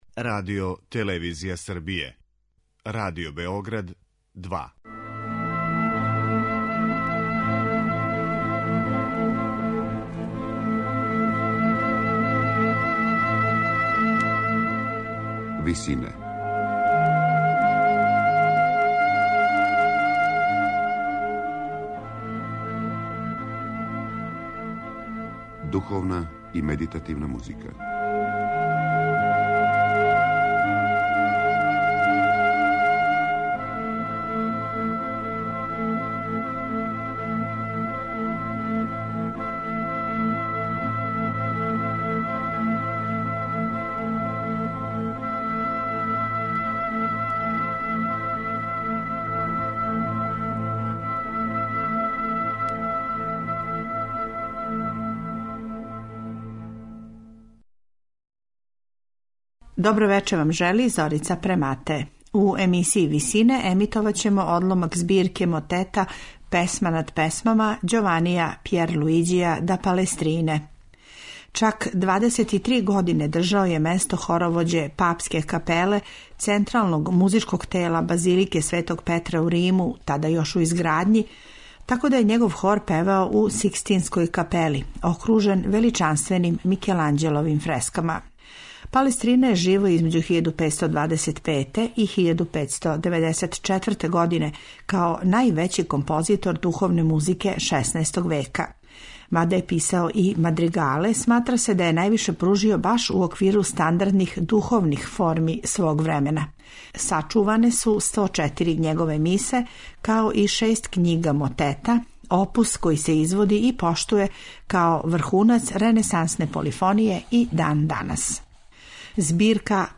Пева камерни хор "Аламире"
На крају програма, у ВИСИНАМА представљамо медитативне и духовне композиције аутора свих конфесија и епоха.